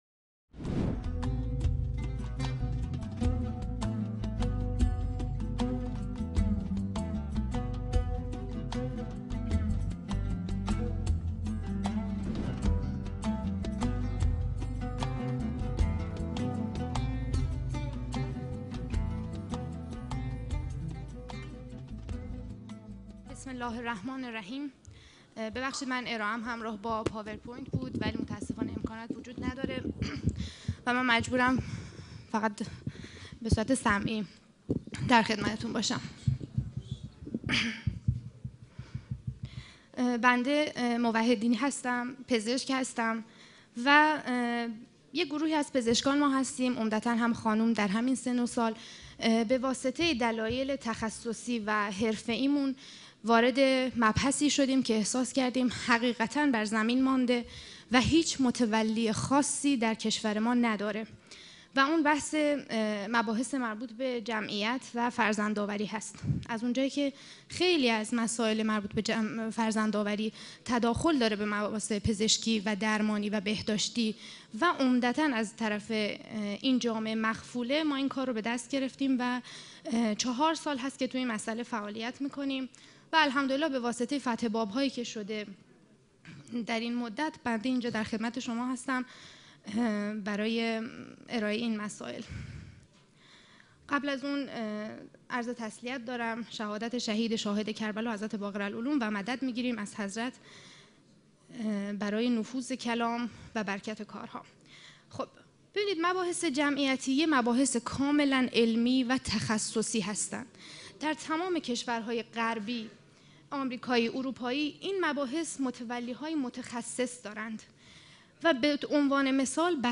سخنرانی
یازدهمین همایش هیأت‌های محوری و برگزیده کشور | شهر مقدس قم - مسجد مقدس جمکران - مجتمع یاوران مهدی (عج)